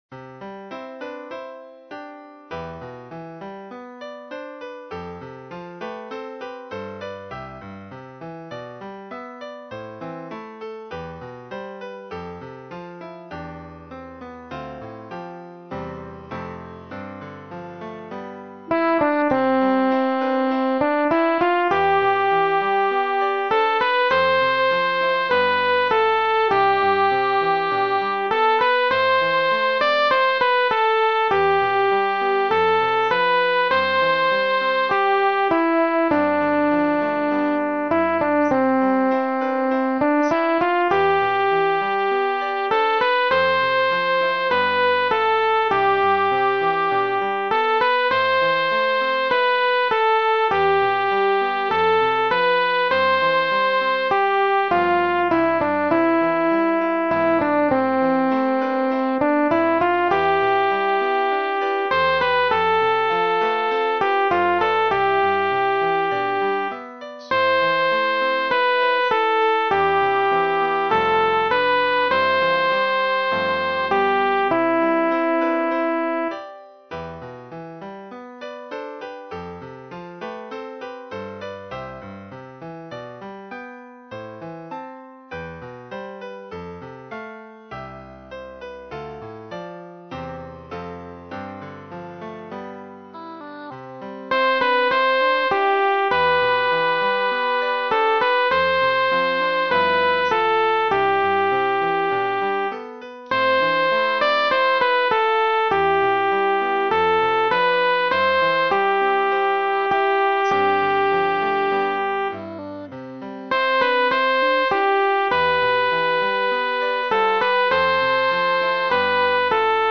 hymn J127 arranged Larsen&Mohlman